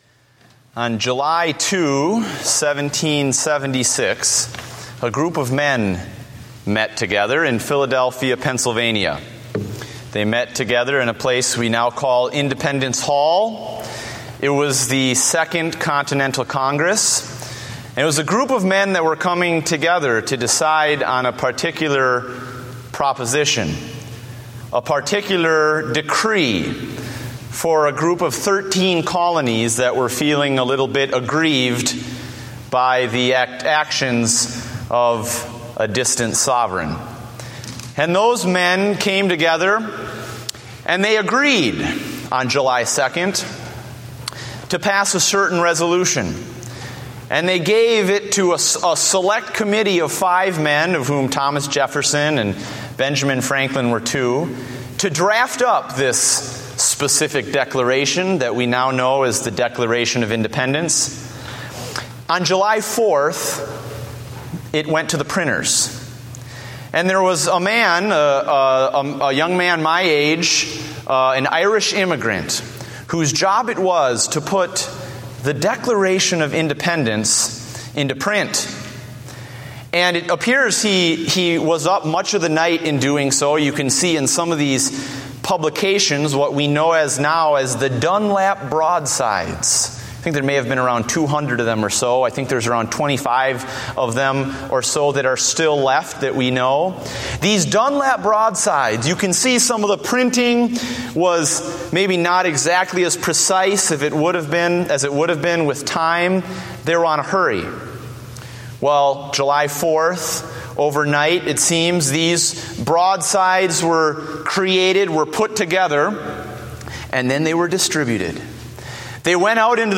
Date: June 29, 2014 (Evening Service)